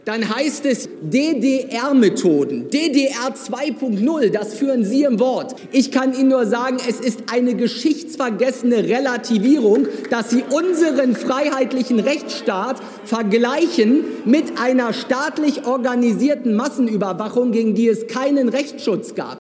Aktuelle Stunde im Bundestag, Titel: KLARE KANTE gegen DEMOKRATIEFEINDE und VERTREIBUNGSPLÄNE…
Er schien seinen Auftritt vorher geübt zuhaben.